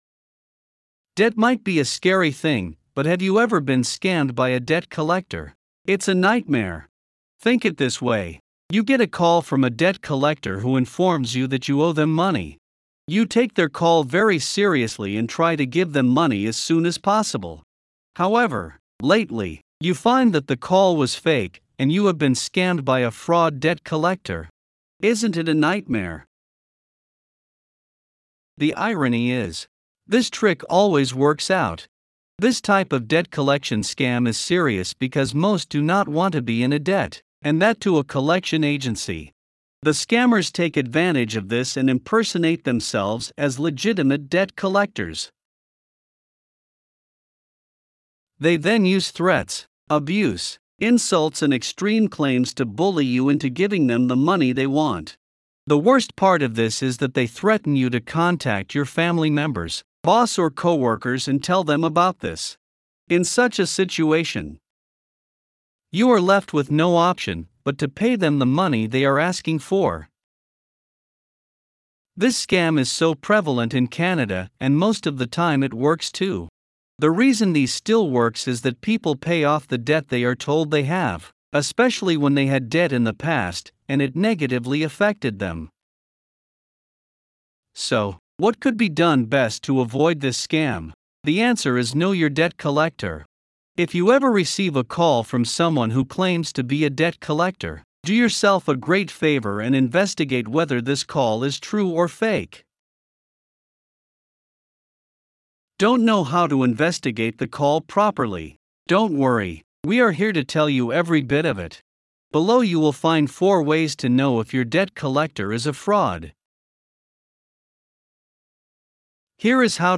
Voiceovers-Voices-by-Listnr_2-1.mp3